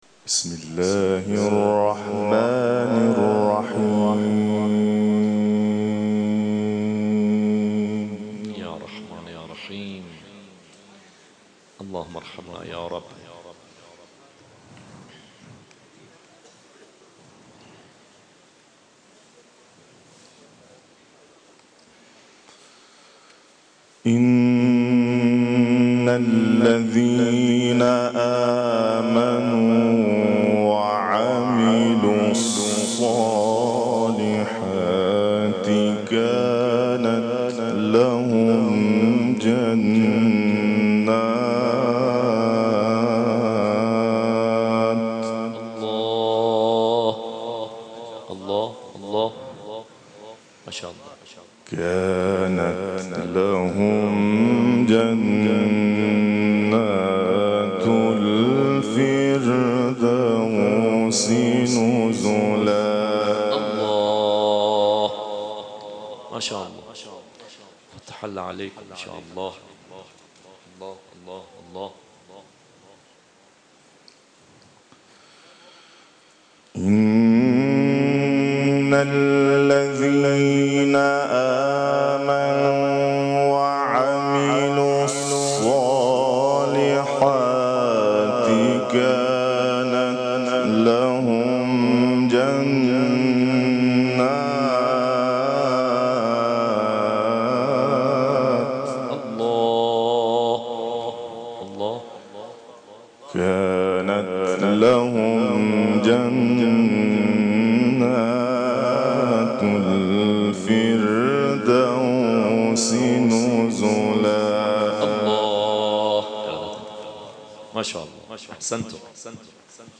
در محفل قرآنی محبان امام رضا(ع)، حسینیه قرآن و عترت مشهد، آیات 107 تا 110 سوره «کهف» و همچنین سوره «اخلاص» را تلاوت کرده که در اختیار علاقه‌مندان قرار گرفته است.
تلاوت